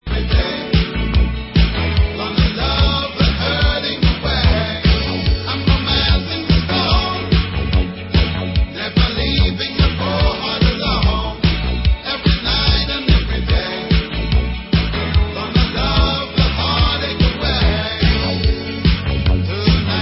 World/Reggae